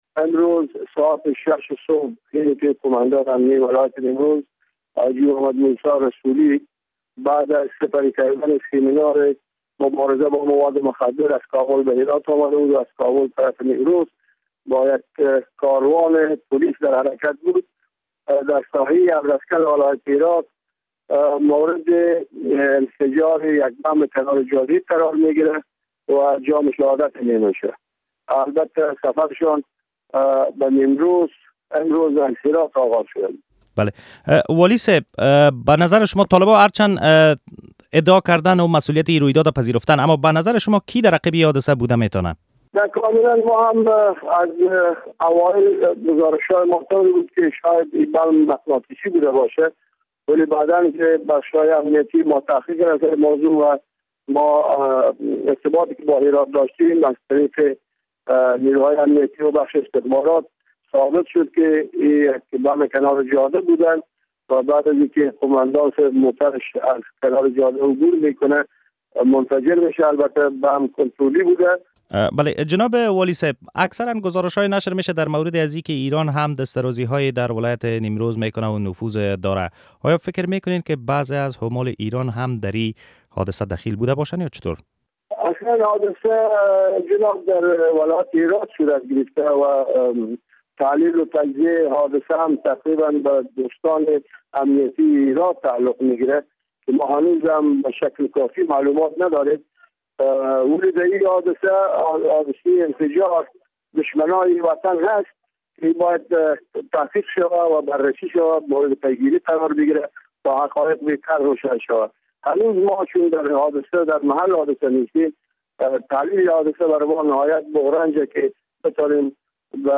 مصاحبه با محمد سرور ثبات والی نیمروز در مورد کشته شدن قوماندان امنیه آن ولایت